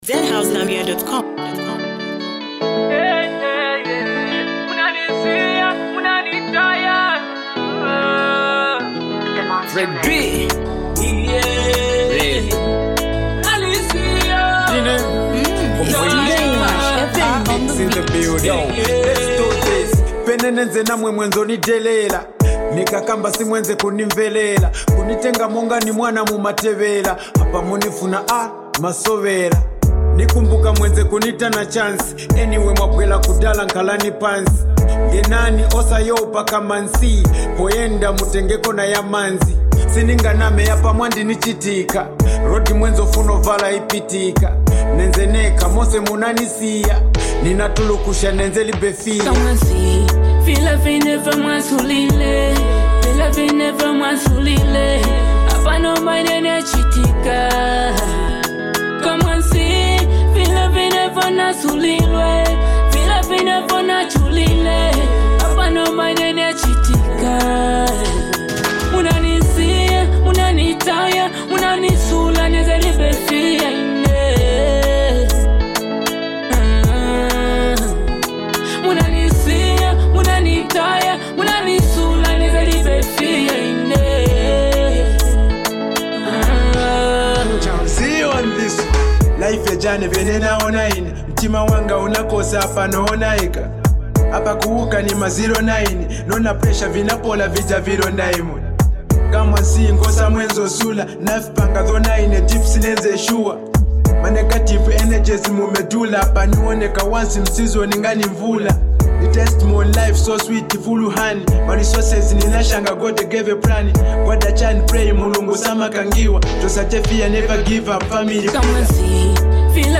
a soulful track